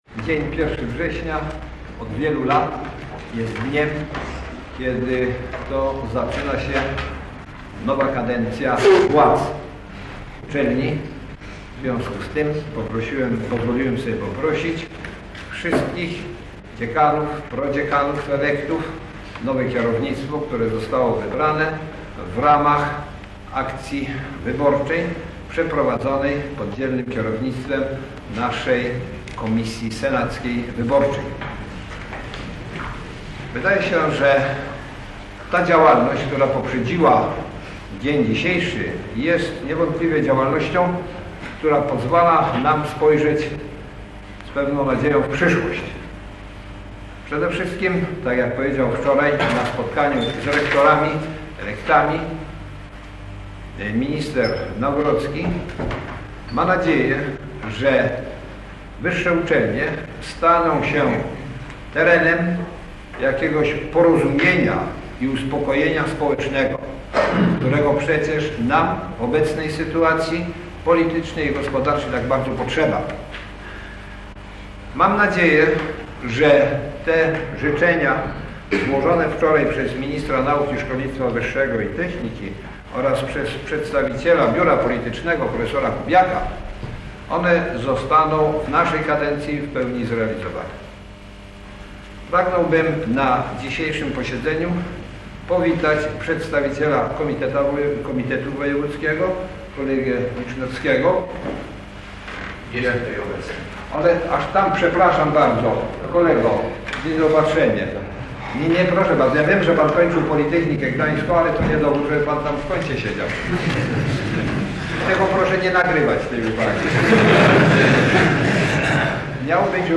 Inauguracja roku akademickiego 1981/82 w PG: relacja [dokument dźwiękowy] - Pomorska Biblioteka Cyfrowa
Wystąpienie rektora PG prof. Jerzego Doerffera